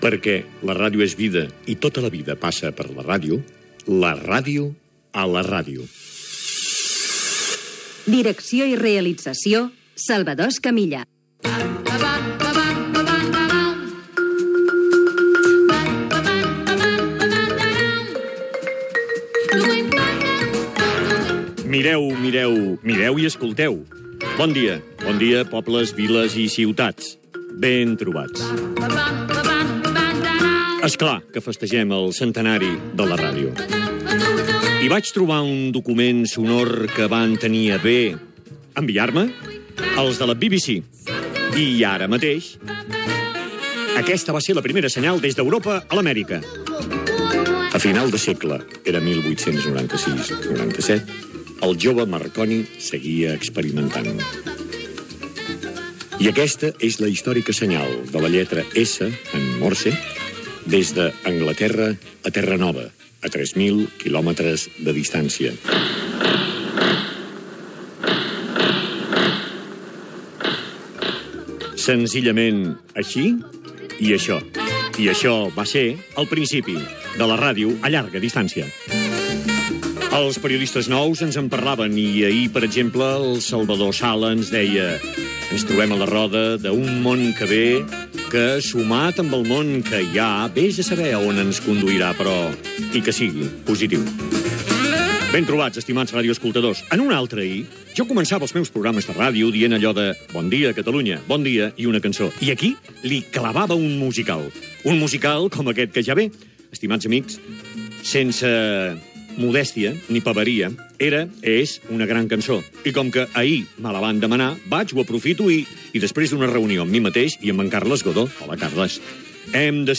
Careta del programa, salutació, senyal de la lletra "S" enviat d'Anglaterra a Terranova per ràdio, tema musical
repàs a alguns dels continguts que s'oferiran, els animals abandonats, la tauromàquia i la mort del torero Manolete (Manuel Rodríguez Sánchez) Gènere radiofònic Entreteniment